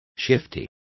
Complete with pronunciation of the translation of shifty.